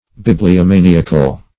Bibliomaniacal \Bib`li*o*ma*ni"ac*al\, a. Pertaining to a passion for books; relating to a bibliomaniac.
bibliomaniacal.mp3